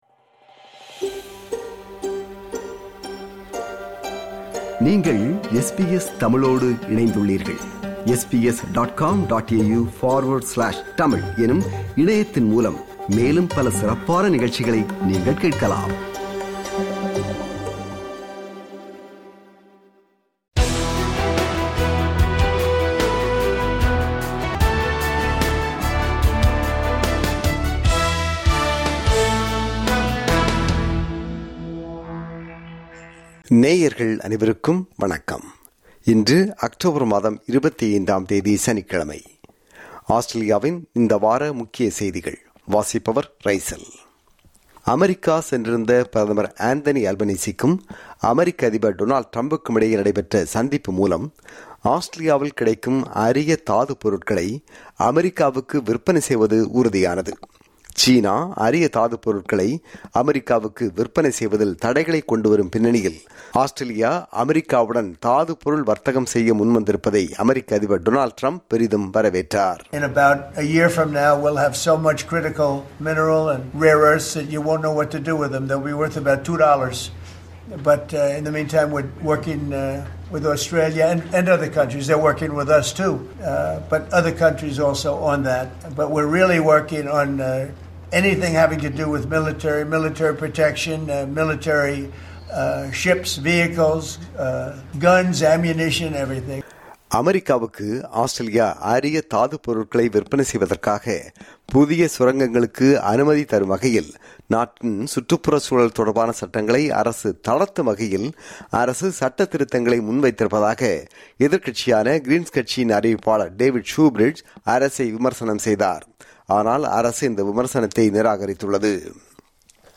ஆஸ்திரேலியாவில் இந்த வாரம் (19–25 அக்டோபர் 2025) நடந்த முக்கிய செய்திகளின் தொகுப்பு.